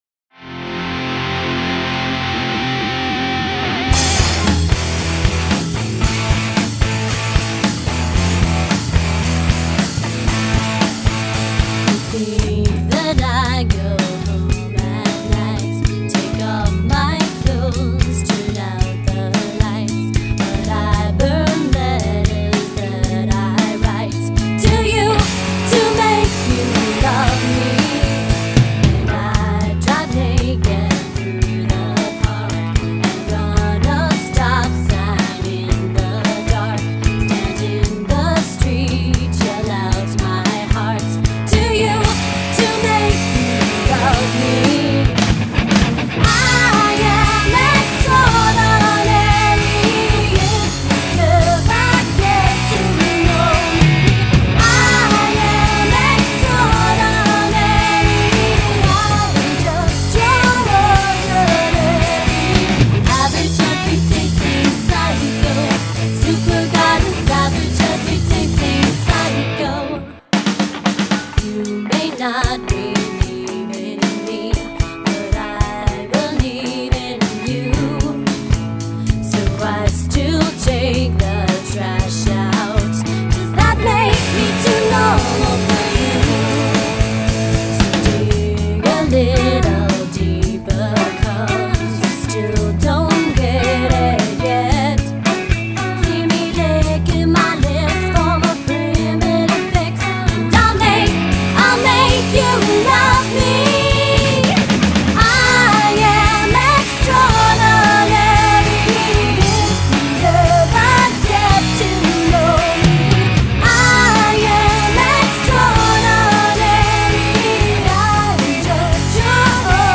Live Music!